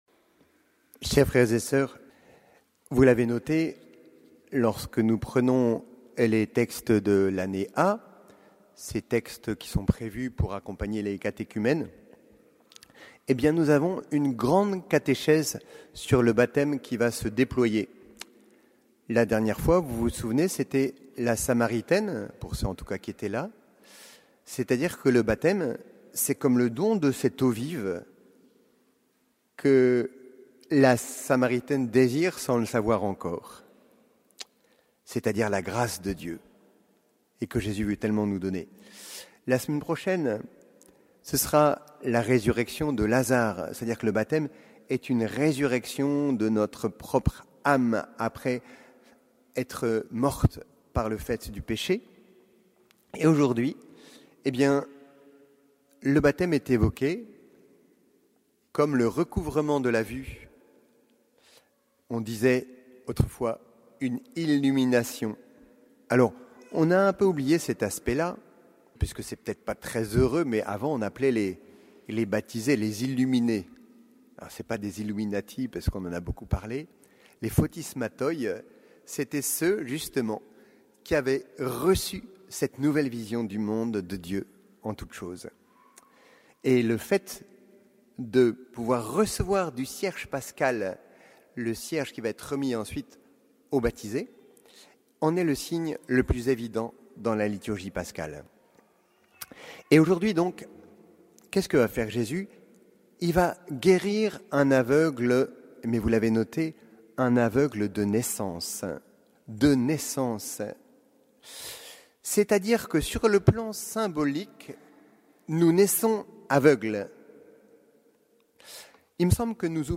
Homélies dominicales